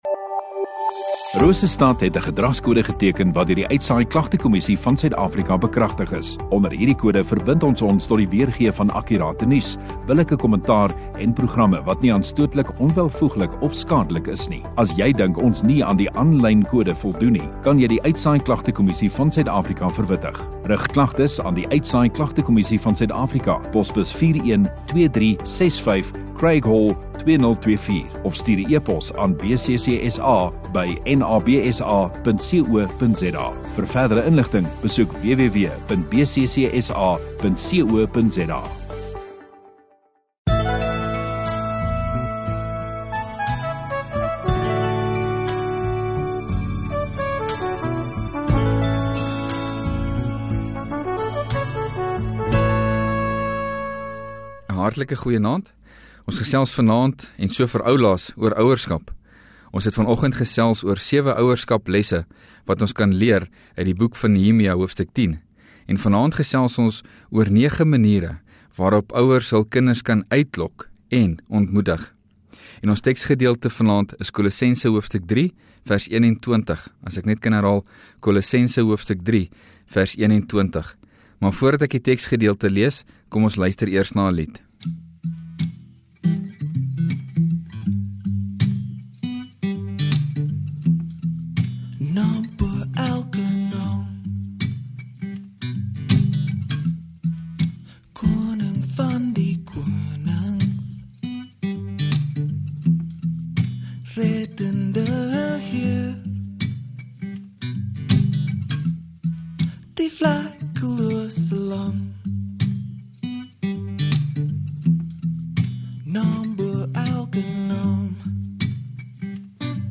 31 Jan Sondagaand Erediens